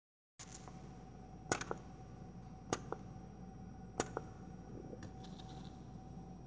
As a wonderful bonus, I can make a very interesting “puff” noise with it. This recording loses the nuances of the perfect puff. The real sound is way cooler than this recording indicates:
New-Brush.wav